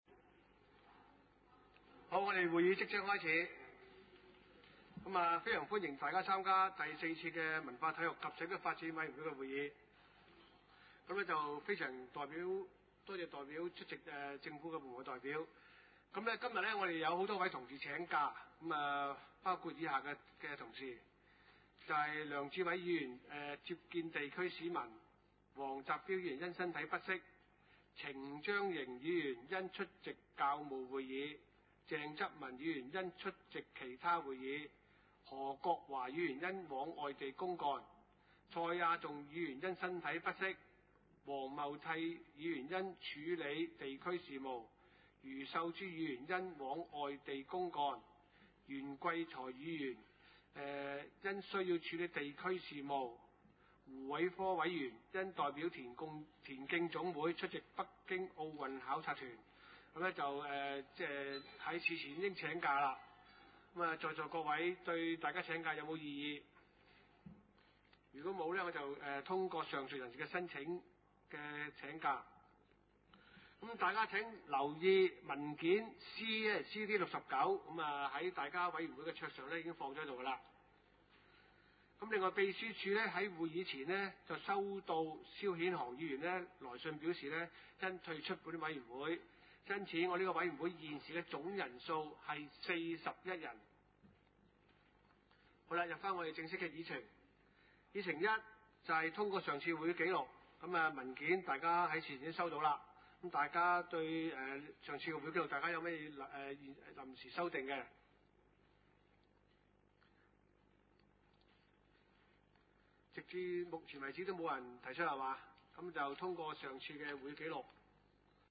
地點：沙田區議會會議室